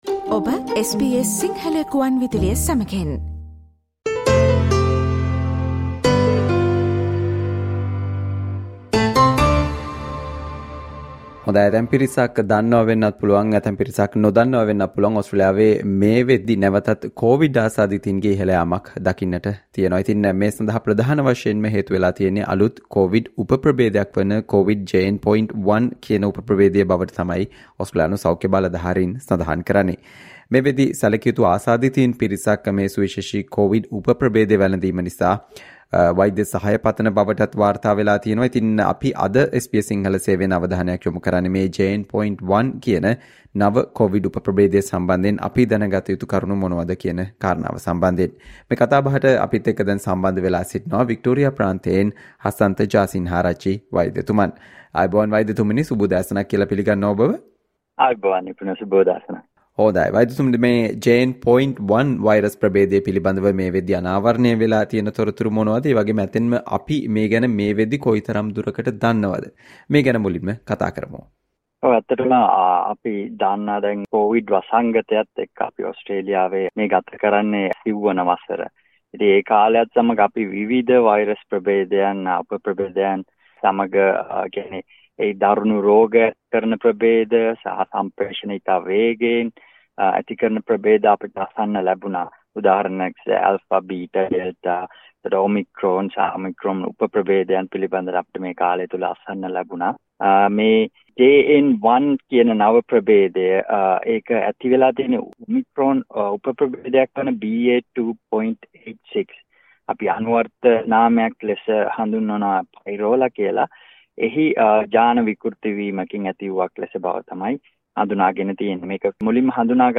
ඕස්ට්‍රේලියාවේ මේ වනවිට පැතිරීමක් දක්නට තිබෙන JN.1 කෝවිඩ් උප ප්‍රබේදයෙන් තිබෙන සෞඛ්‍යමය අවදානම සහ එමගින් ආරක්ෂා වීමට ගතයුතු පියවර පිළිබඳව SBS සිංහල සේවය සිදු කල සාකච්චාවට සවන් දෙන්න